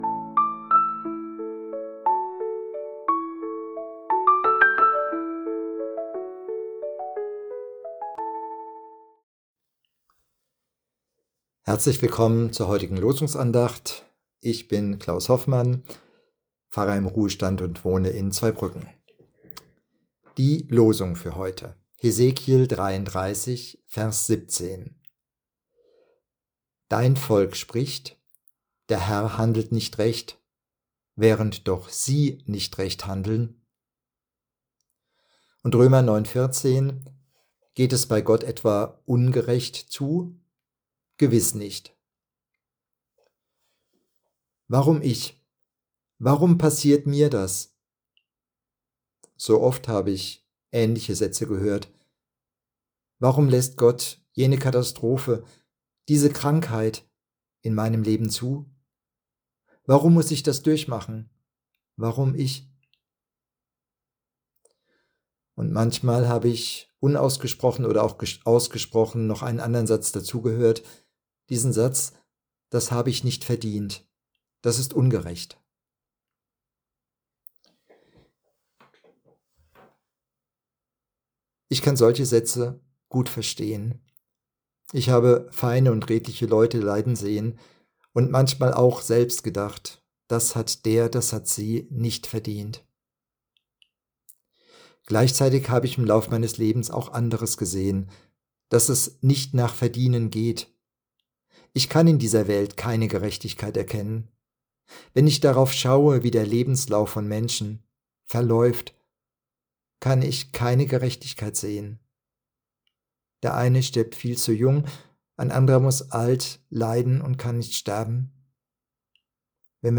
Losungsandacht für Freitag, 09.05.2025